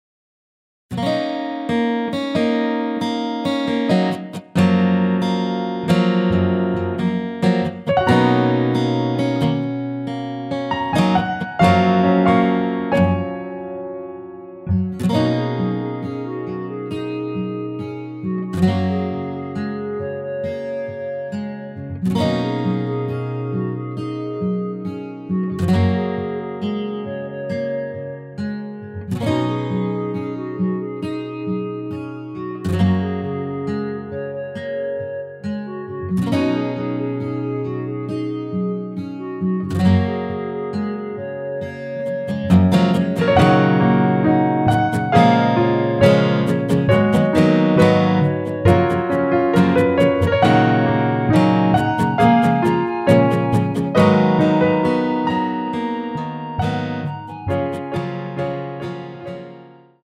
원키에서(+7) 올린 멜로디 포함된 MR 입니다.(미리듣기 참조)
F#
앞부분30초, 뒷부분30초씩 편집해서 올려 드리고 있습니다.